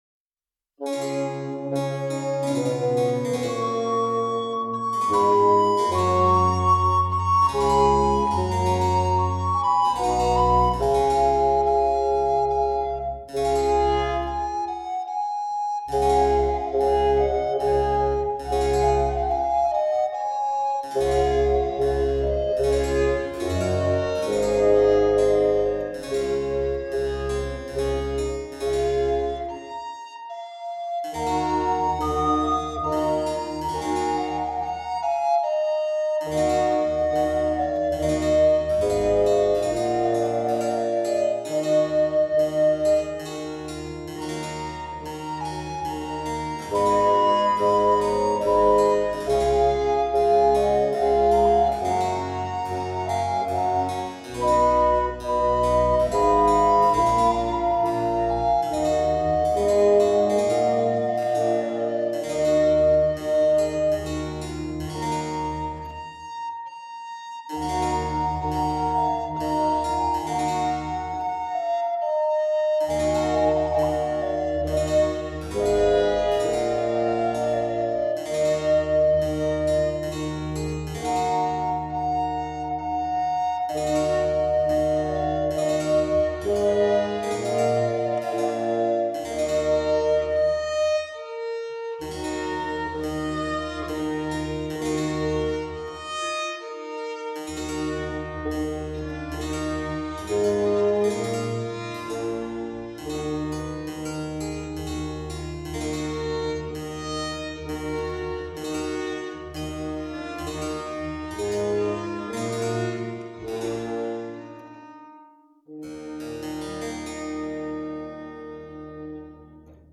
Música tradicional